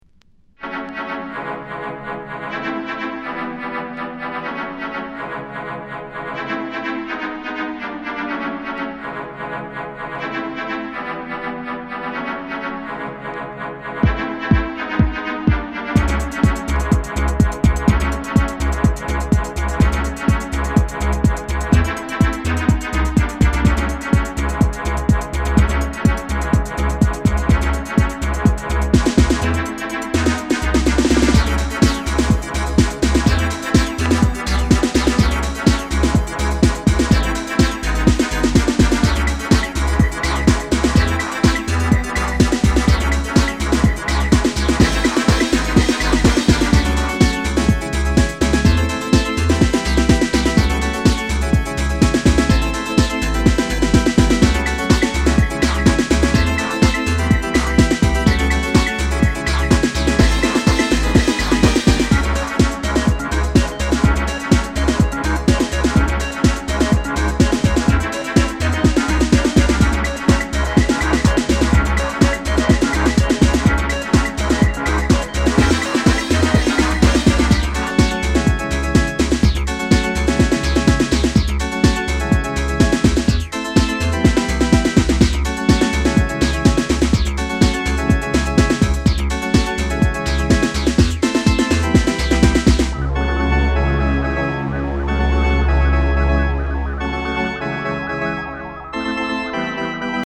グラウンドビートチルアウト